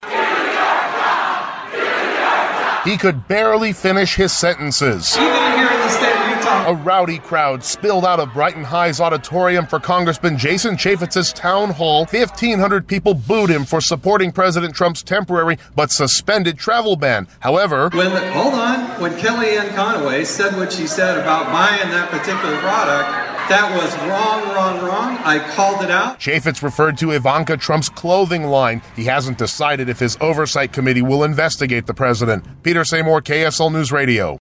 Angry town hall crowd jeers Rep. Chaffetz
The overflow crowd at Brighton High in Cottonwood Heights drowned out most of Chaffetz's answers to their questions. He cut the town short.